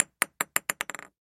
Звуки настольного тенниса
Пластиковый мячик упал на стол